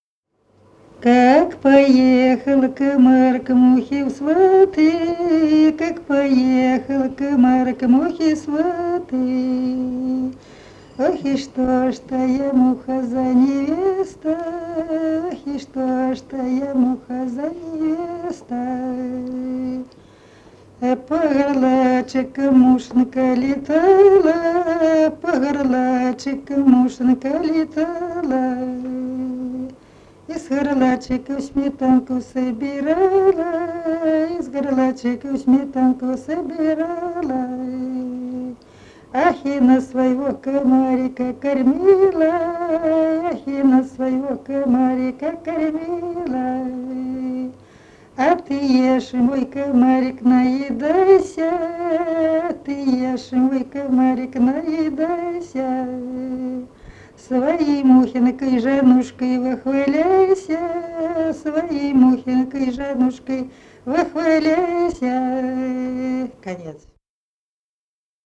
Народные песни Смоленской области
«Как поехал комар» (вечерочная шуточная).